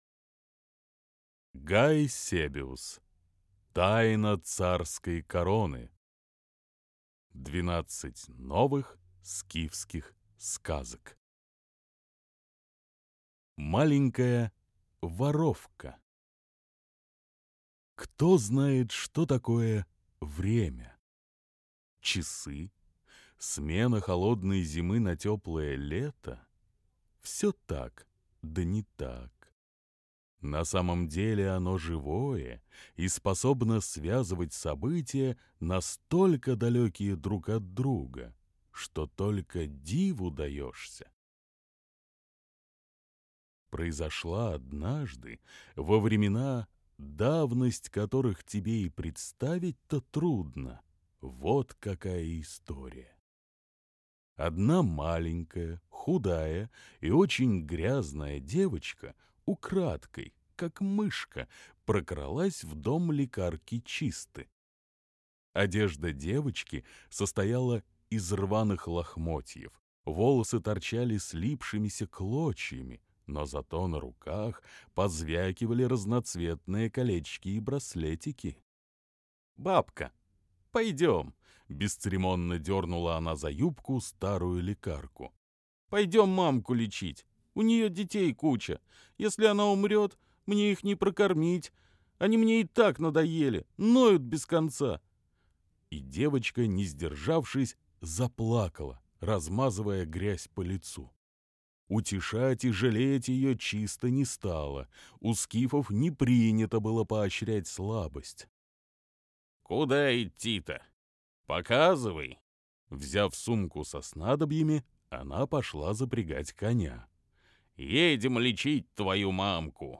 Аудиокнига Тайна царской короны. 12 новых скифских сказок | Библиотека аудиокниг